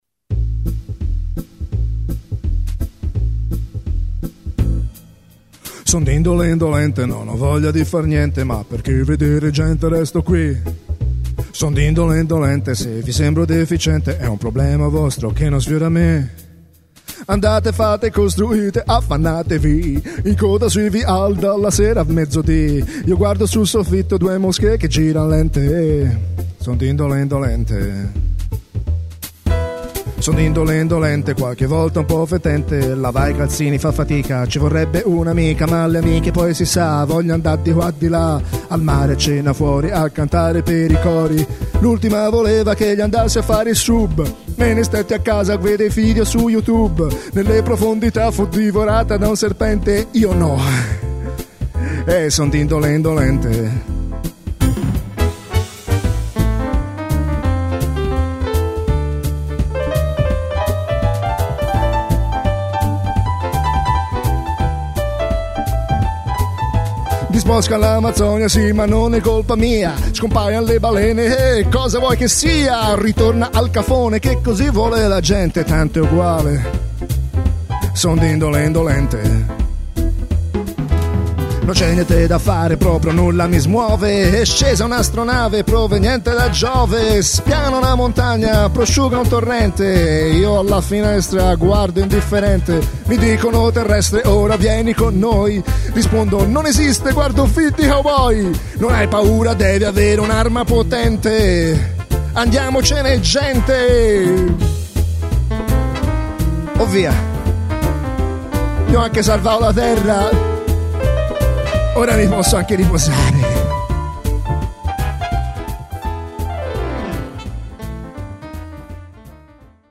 Un allegro pezzo swing in cui ironizzo sulla mia pigrizia